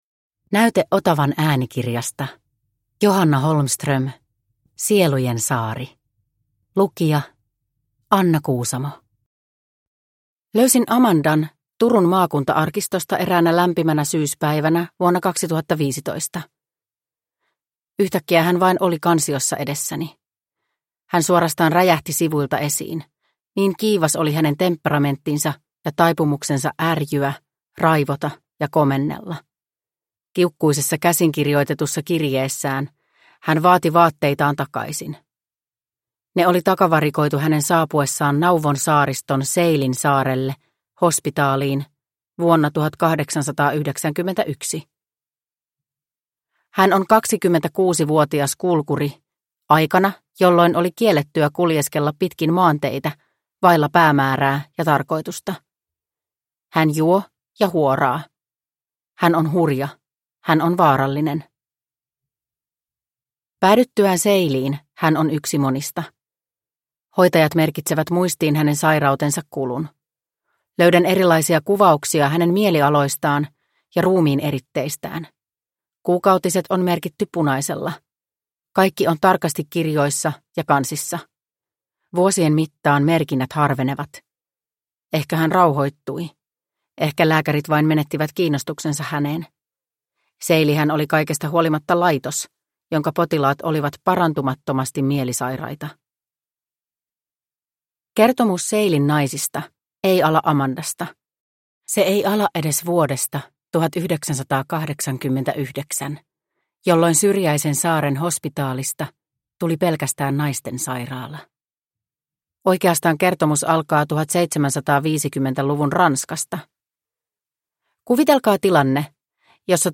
Sielujen saari – Ljudbok – Laddas ner